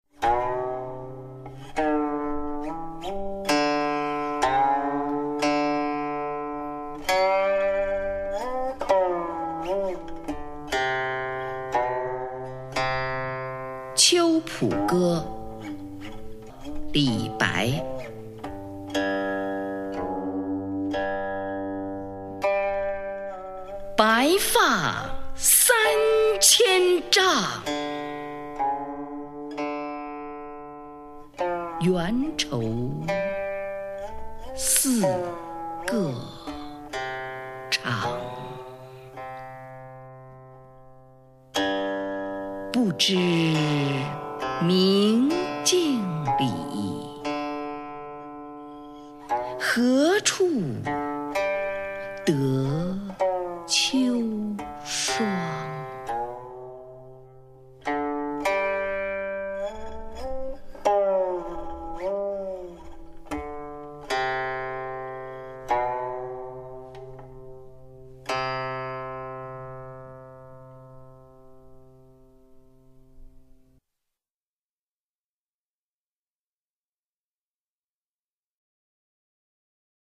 [隋唐诗词诵读]李白-秋浦歌（女） 唐诗吟诵